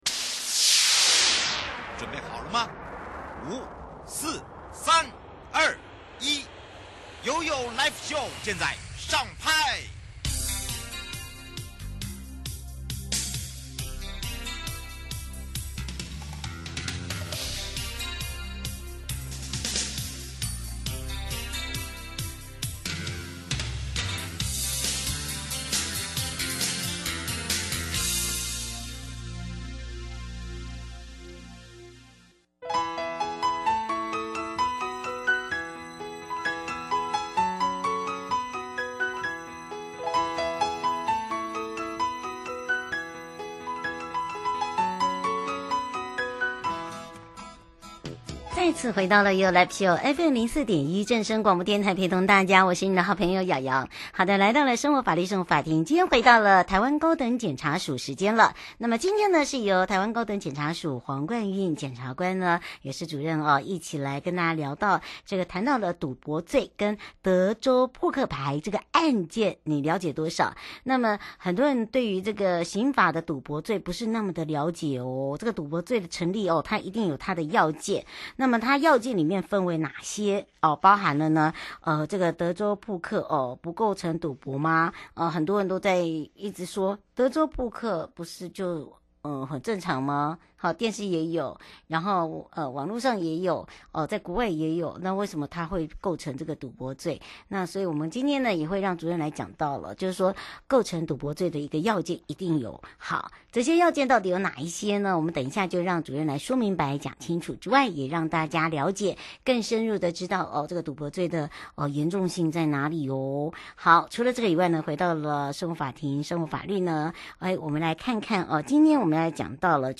受訪者： 臺灣高等檢察署黃冠運檢察官 節目內容： 談賭博罪與德州撲克案件？